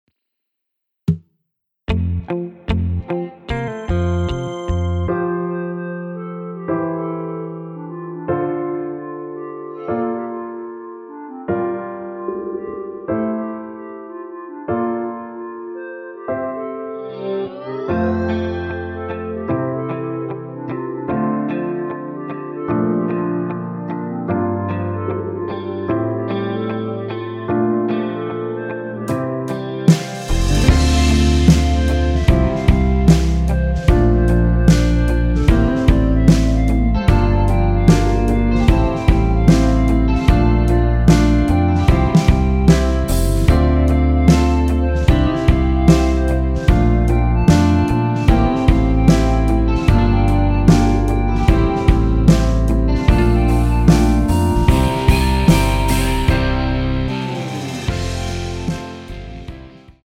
원키에서(-1)내린 멜로디 포함된 MR입니다.(미리듣기 확인)
앞부분30초, 뒷부분30초씩 편집해서 올려 드리고 있습니다.